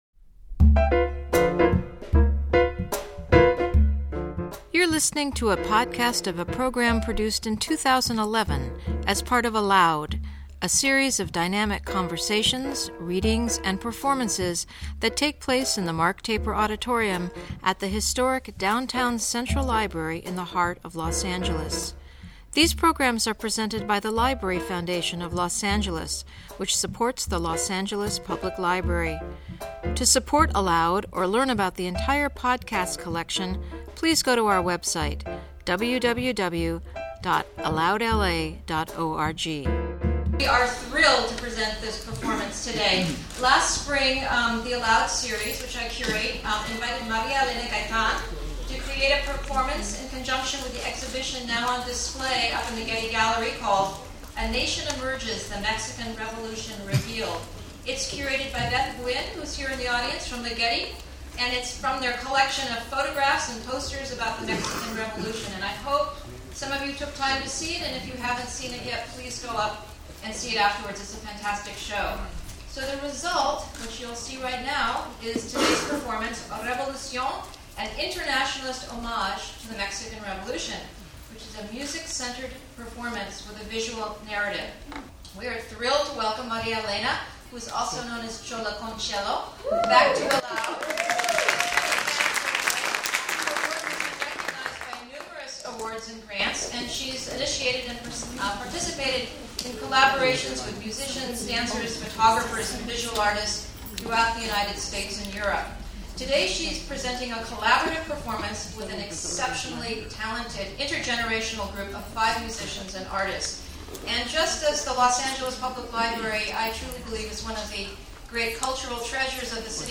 violoncello, narration
vocals
composer, guitar, vocals, visuals
accordion, vocals